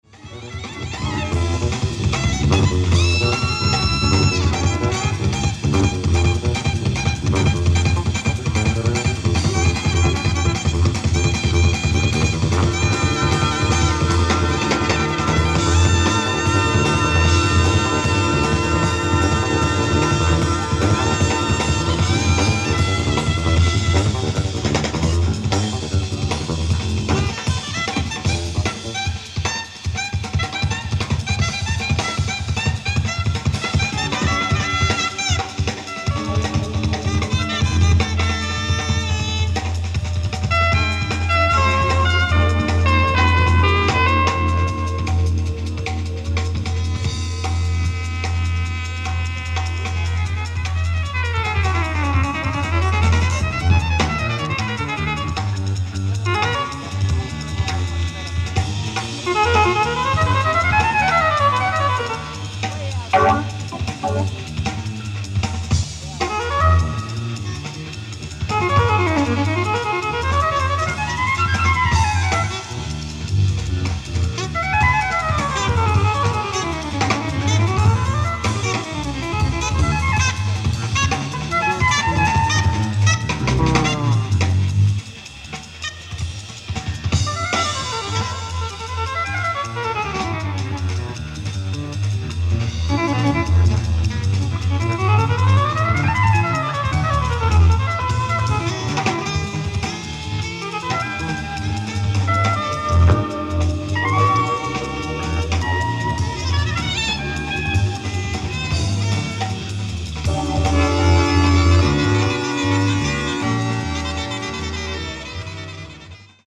海外マニアによるピッチ修正版！！
※試聴用に実際より音質を落としています。